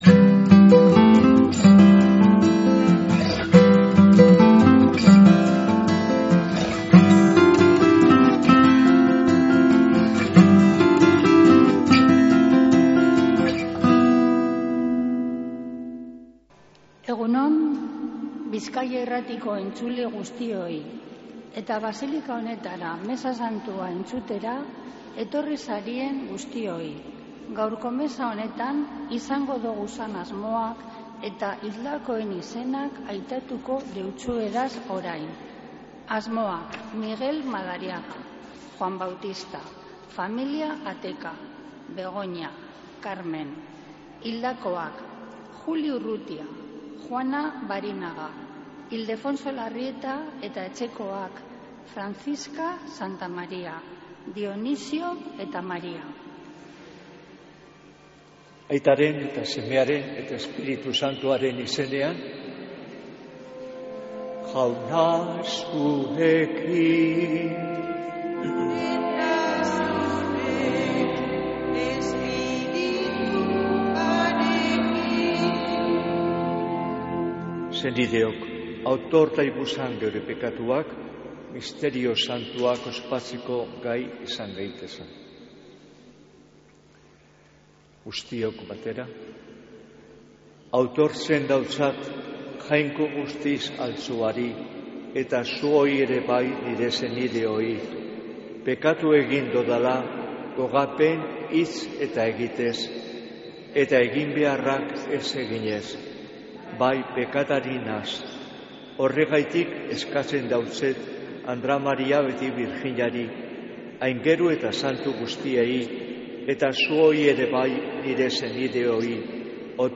Mezea zuzenean Begoñatik